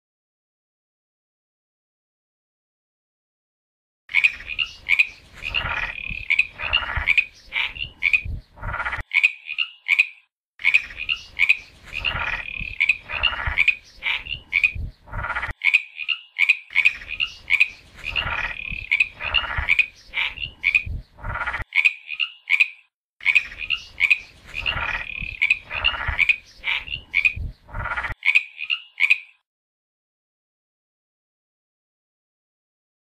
RANITA DE SAN ANTONIO
Sonido de la Rana.sonidos cortos de animales.mp3